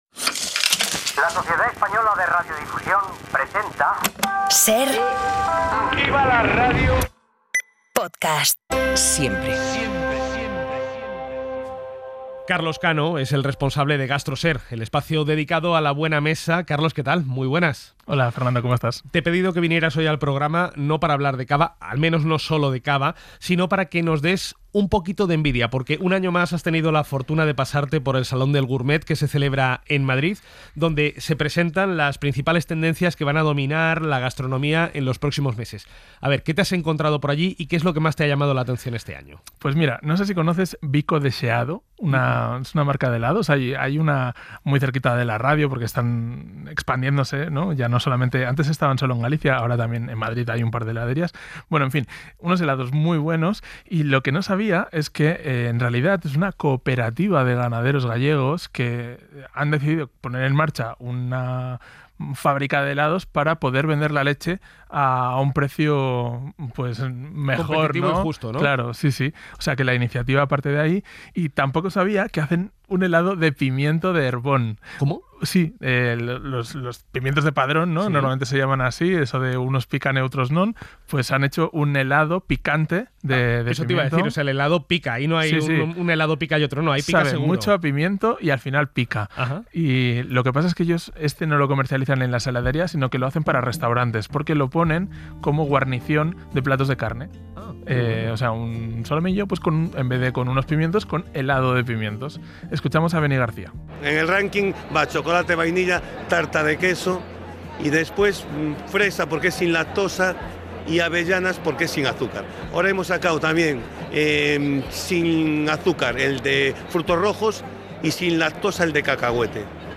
Y entrevistamos al chef gallego